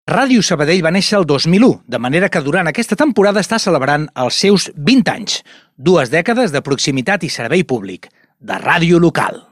Emès amb motiu del Dia Mundial de la Ràdio 2022.